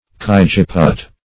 cajeput - definition of cajeput - synonyms, pronunciation, spelling from Free Dictionary Search Result for " cajeput" : The Collaborative International Dictionary of English v.0.48: Cajeput \Caj"e*put\, n. See Cajuput .